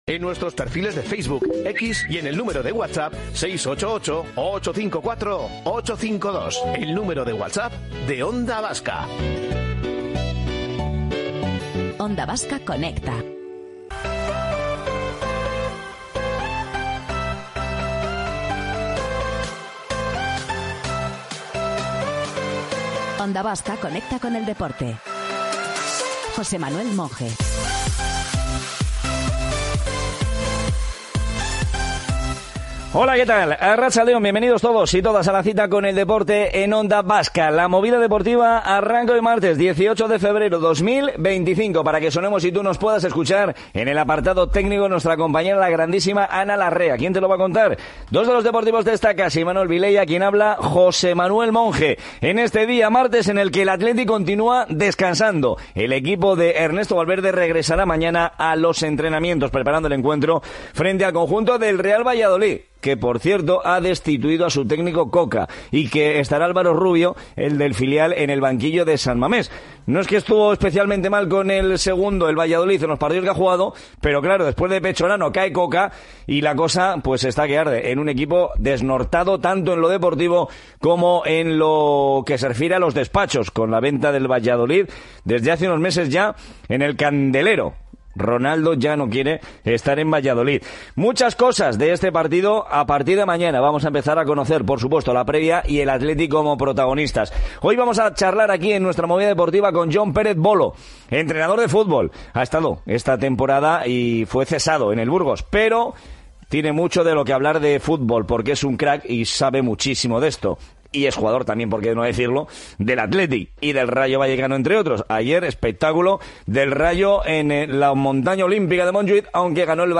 Voor het eerst namen we LIVE een nieuwe aflevering van Aap Noot Mishima op, dé Nederlandstalige podcast over Japanse literatuur en cultuur, zorgvuldig geplaatst in de juiste historische context.